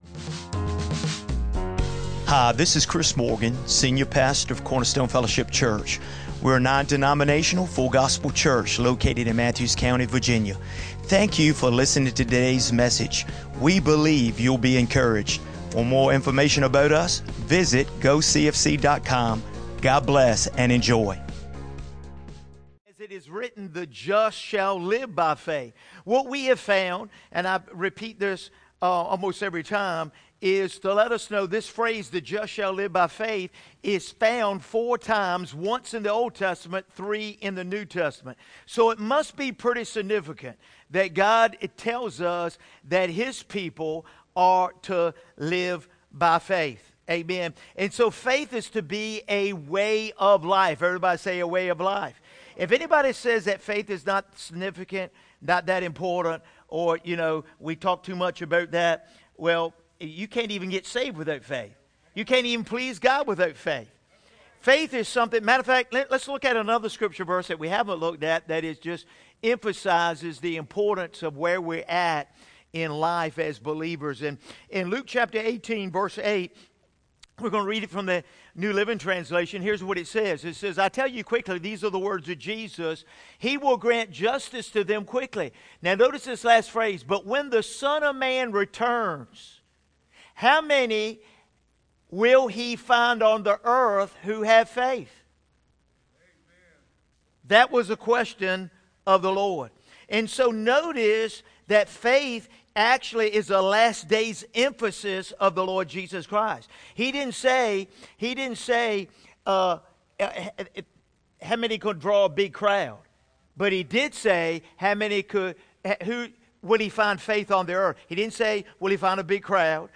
Learn the biblical principles that have empowered countless believers to conquer fear, doubt, and adversity. This sermon will inspire and equip you to stand strong, trust in God’s promises, and experience victory in every area of your life.